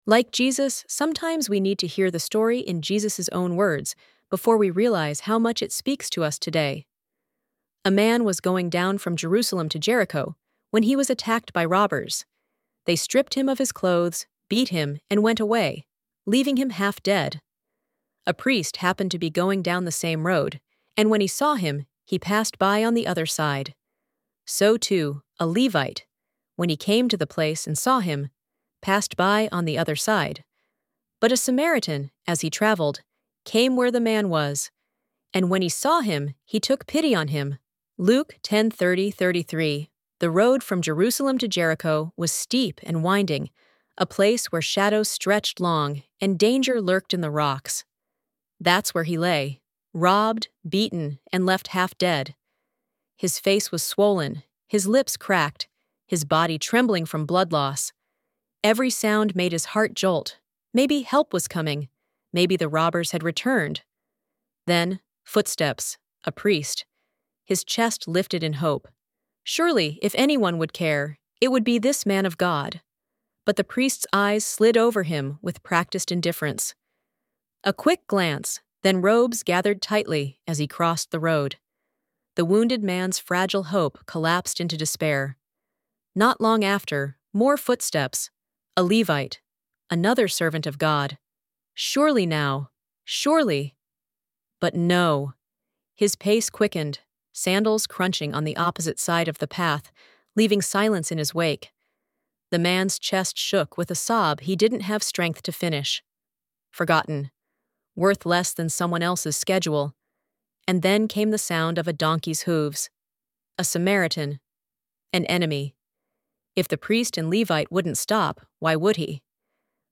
Like Jesus — Audio Reading